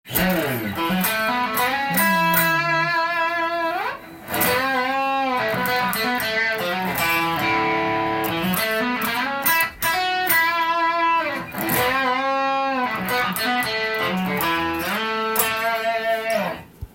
セミホロウボディーになっているので、箱鳴りする感じで
試しに弾いてみました
歪ませてメロディーを弾くと音が伸びて気持ち良く弾くことが出来ました。
P90とセミホロボディーの相性抜群です。